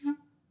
Clarinet / stac
DCClar_stac_D3_v1_rr1_sum.wav